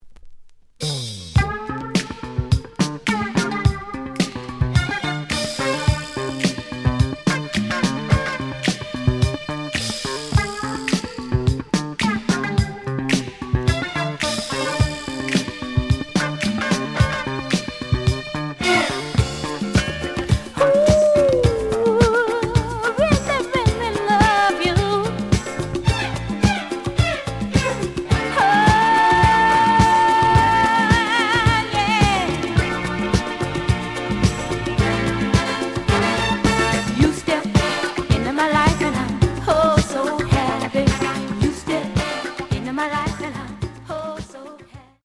The audio sample is recorded from the actual item.
●Genre: Disco
Looks good, but slight noise on parts of both sides.